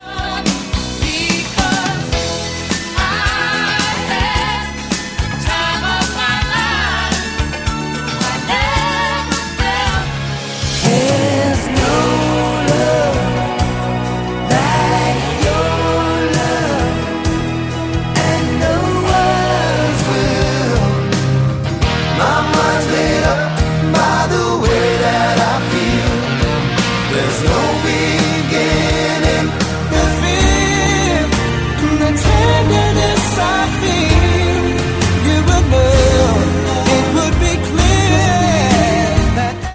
Instrumentation example: